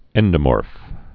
(ĕndə-môrf)